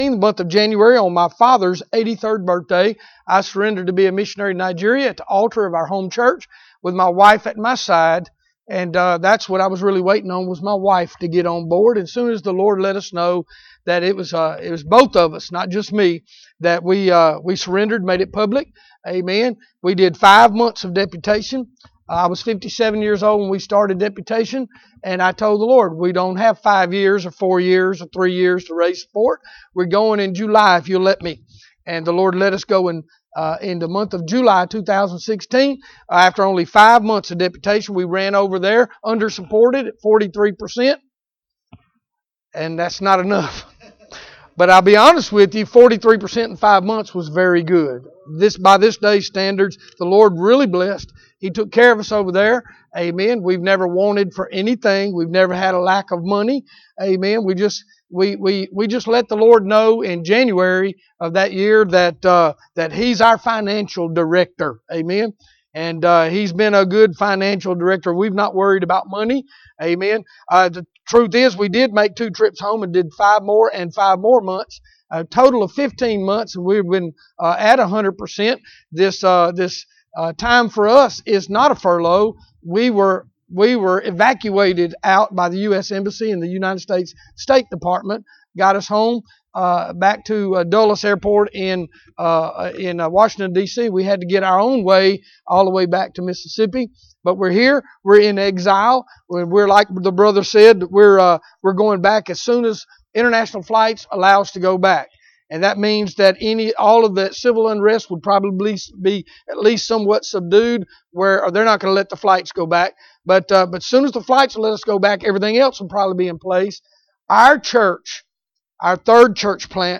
Acts 1:8 Service Type: Mission Conference Bible Text